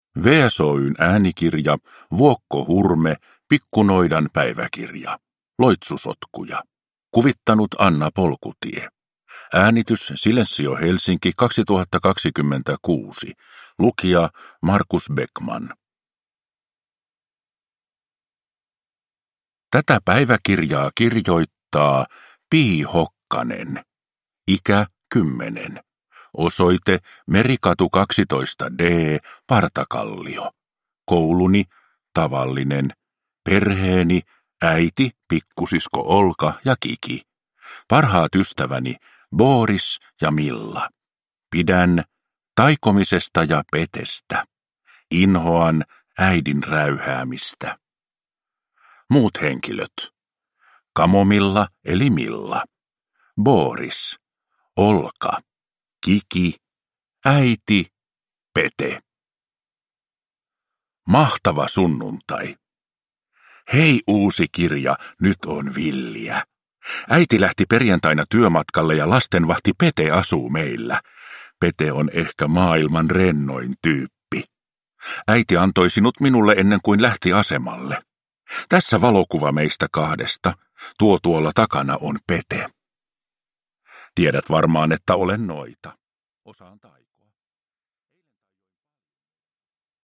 Pikku noidan päiväkirja: Loitsusotkuja (ljudbok) av Vuokko Hurme